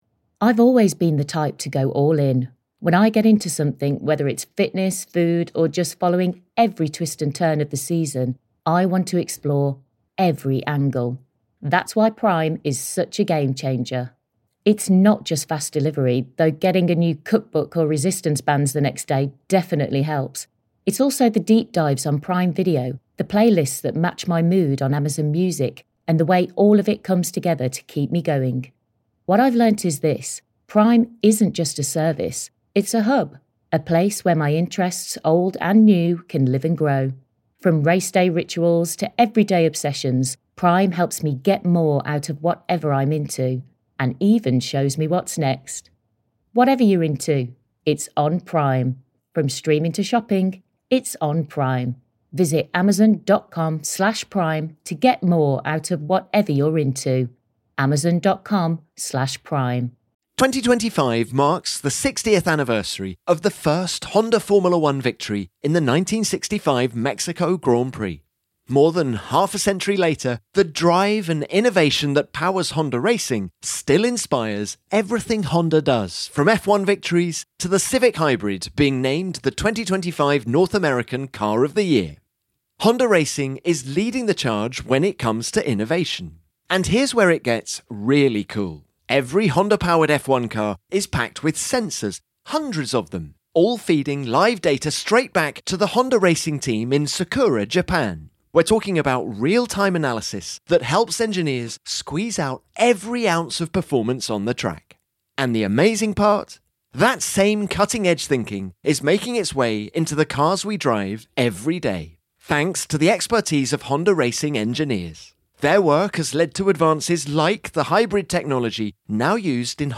is joined in the paddock